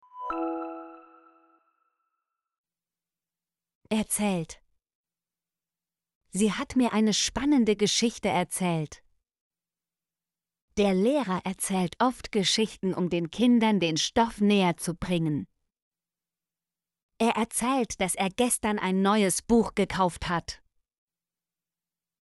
erzählt - Example Sentences & Pronunciation, German Frequency List